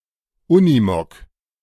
The Unimog (pronunciation in American English: YOU-nuh-mog; British English: YOU-knee-mog;[1] German: [ˈʊnɪmɔk],
De-Unimog.ogg.mp3